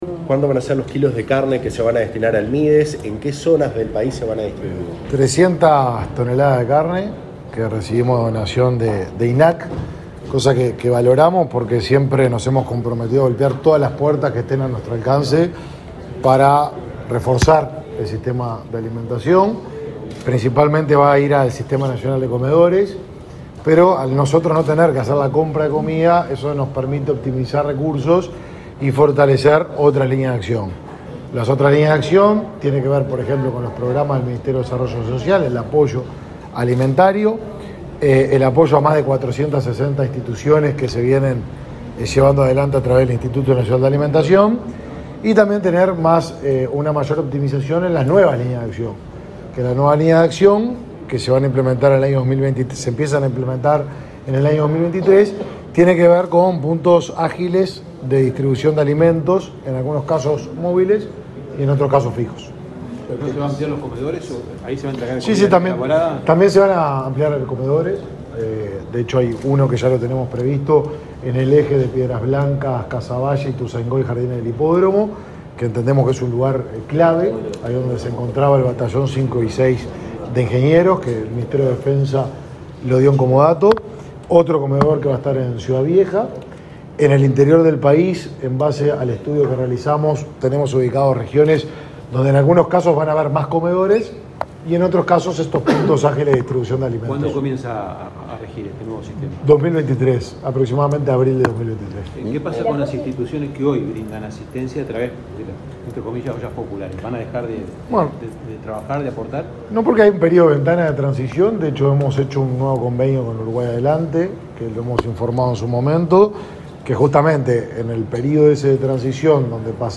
Declaraciones del ministro de Desarrollo Social, Martín Lema
Luego dialogó con la prensa.